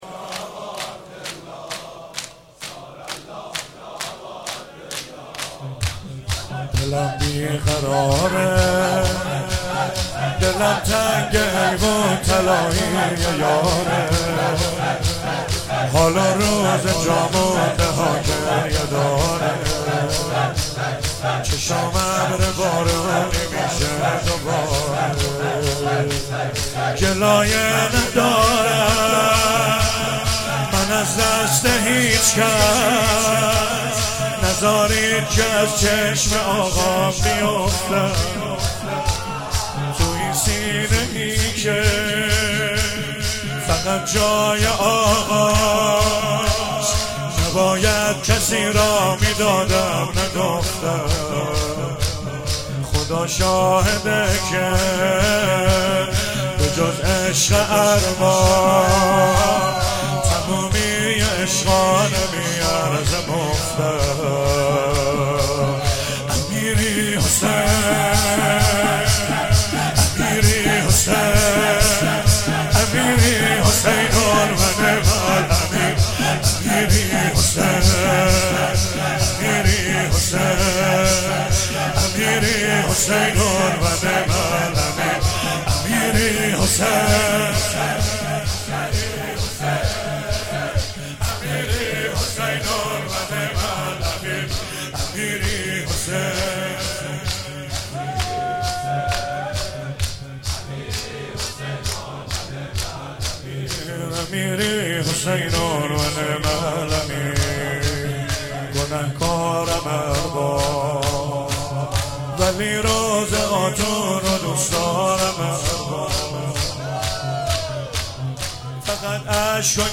شب پنجم محرم97 - شور - دلم بی قرار دلم تنگه